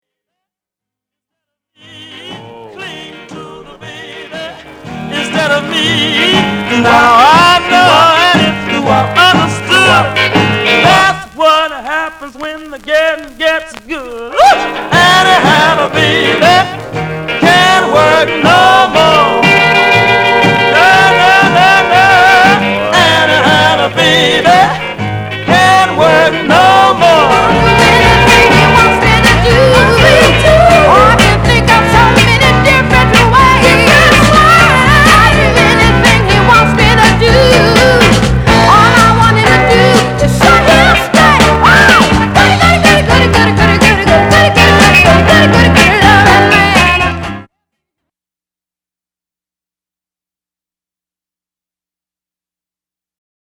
/盤質/両面全体的に細かい傷あり再生良好/US PRESS